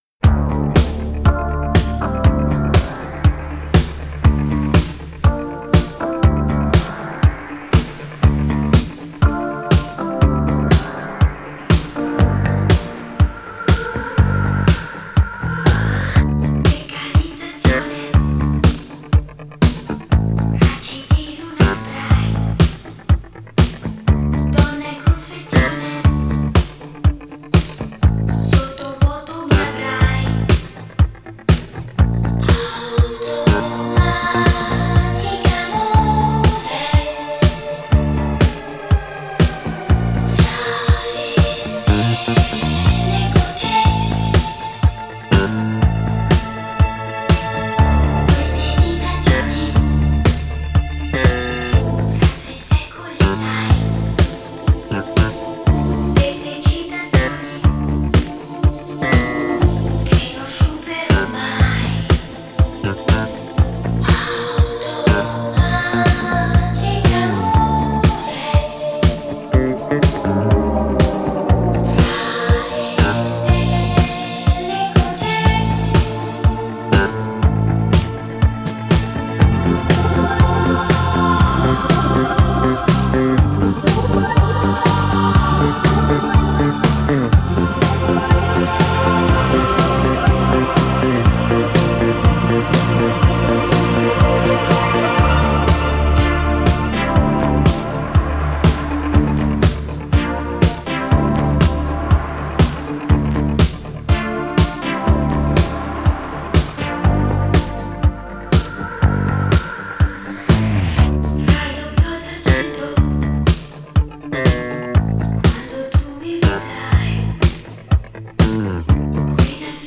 cosmic italian disco anthem
sci-fi outer space vocals